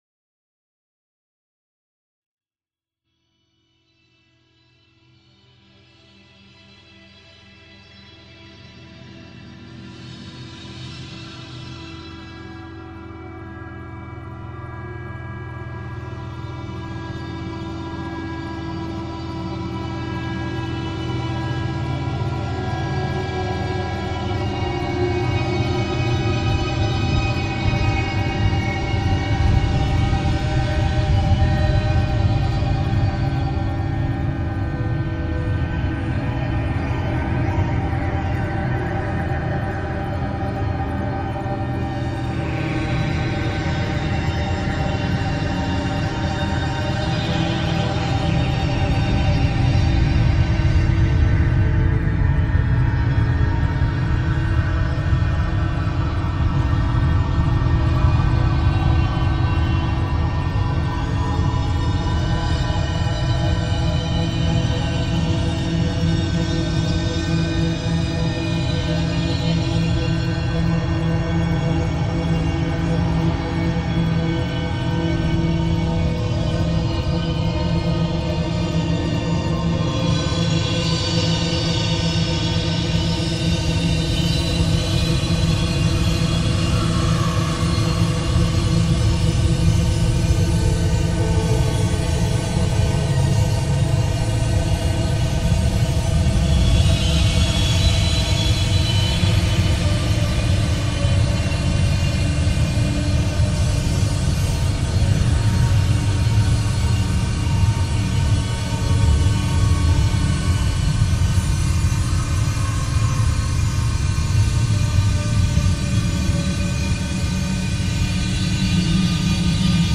File under: Industrial / Experimental